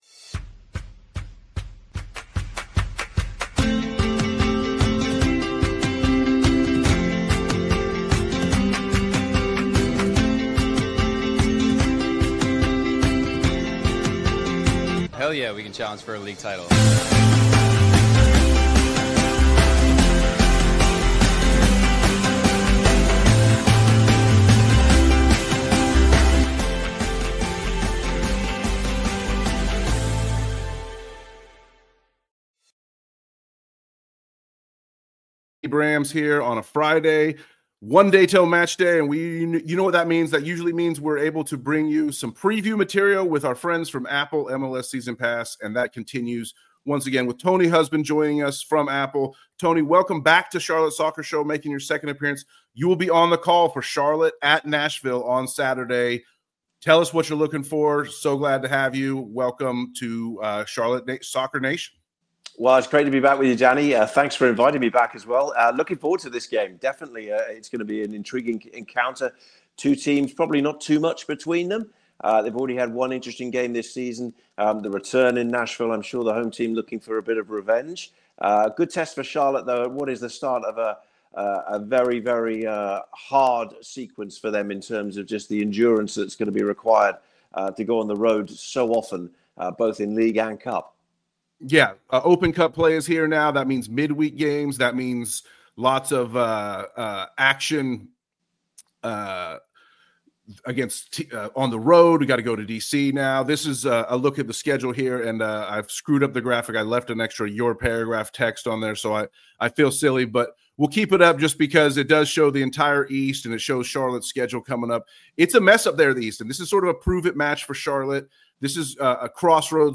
Two supporters and media pros talk Charlotte as a Soccer City.